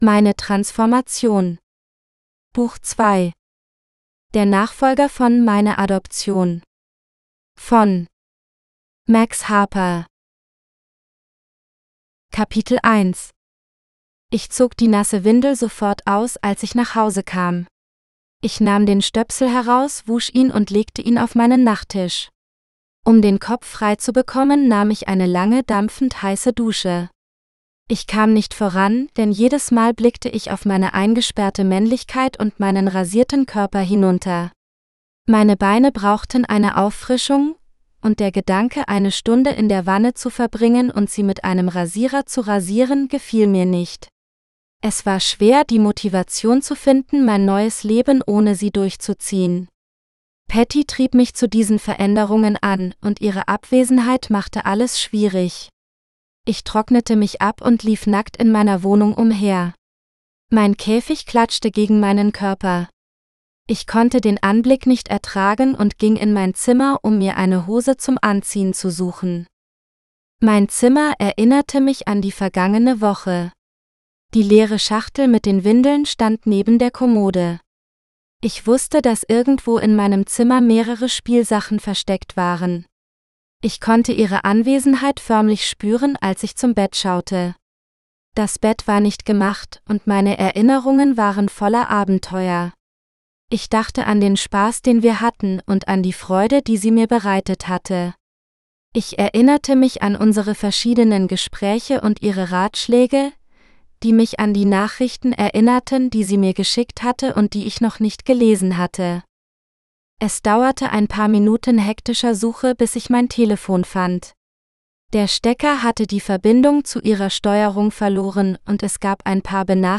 My Transformation GERMAN (AUDIOBOOK – female): $US5.75